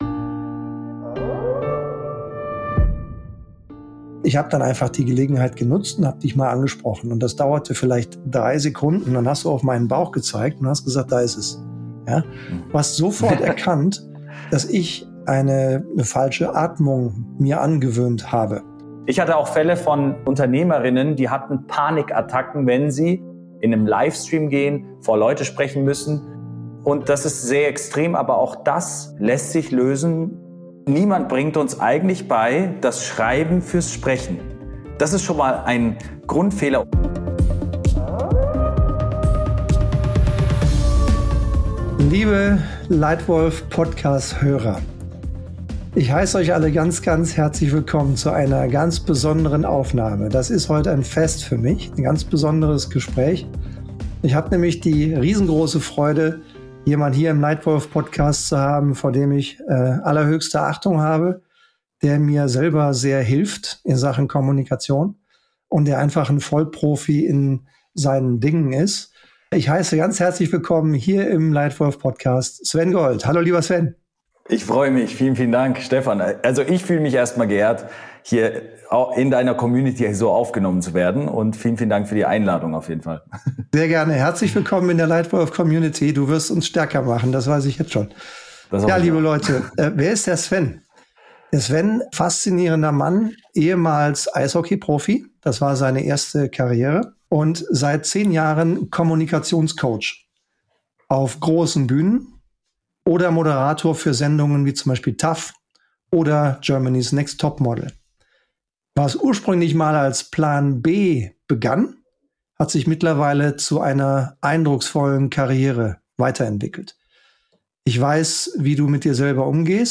In diesem Gespräch geht es darum, wie Du mit Klartext Deine Kommunikation auf ein neues Level bringst. Welche Techniken helfen Dir, authentisch und überzeugend zu sein? Wie kannst Du Dein Team mit den richtigen Worten inspirieren?